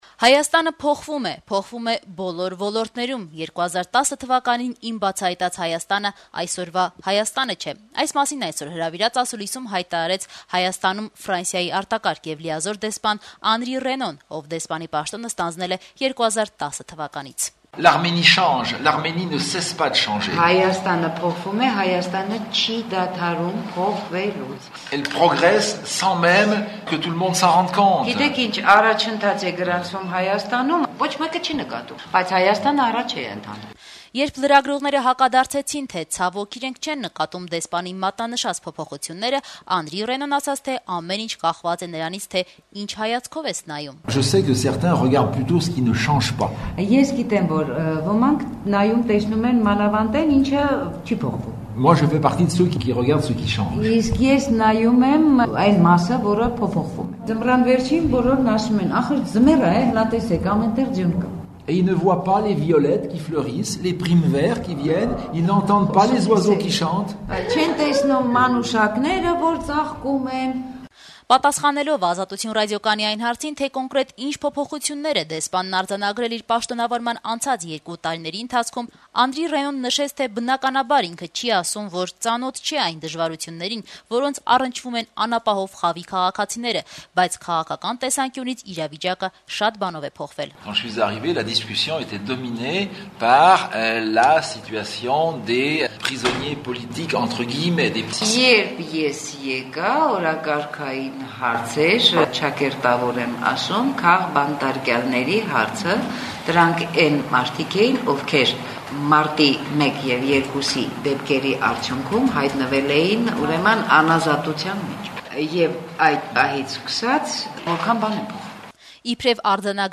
2010 թվականին իմ բացահայտած Հայաստանը այսօրվա Հայաստանը չէ, այսօր հրավիրած ասուլիսում հայտարարեց Հայաստանում Ֆրանսիայի արտակարգ եւ լիազոր դեսպանը։